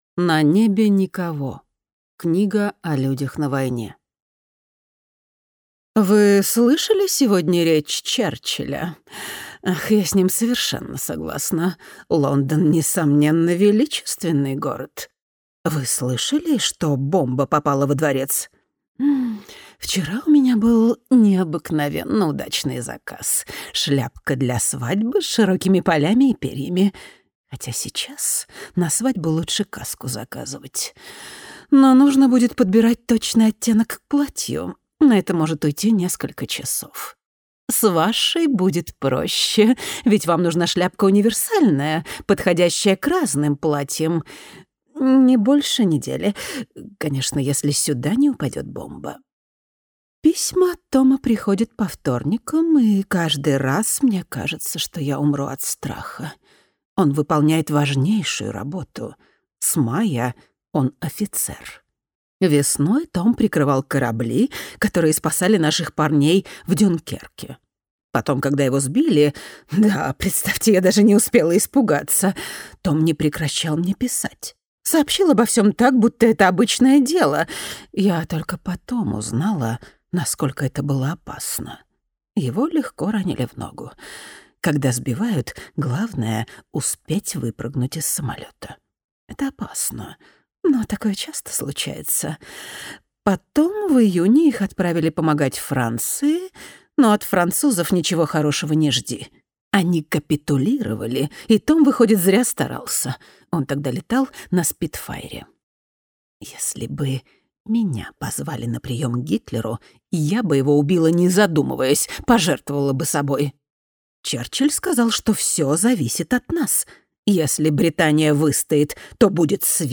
Аудиокнига На небе никого | Библиотека аудиокниг